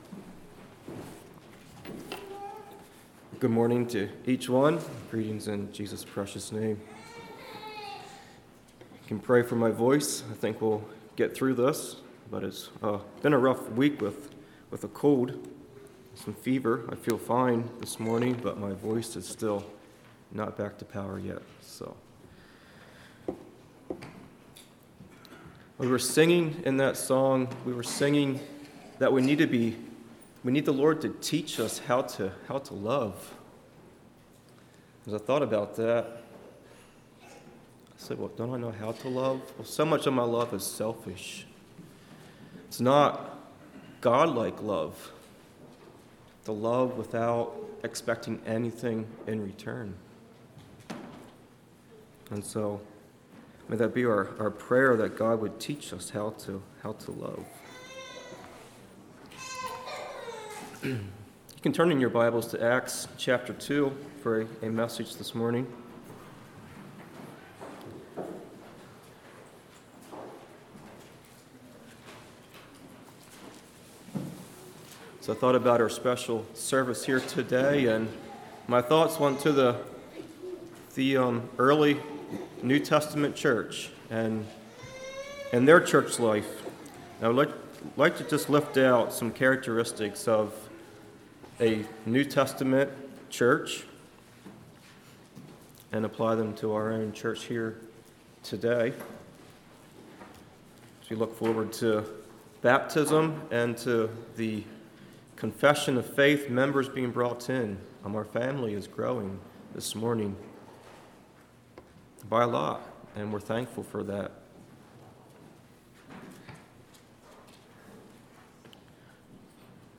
38:24 Summary: A sermon on church membership, the basis for the church. When was the church at it's greatest?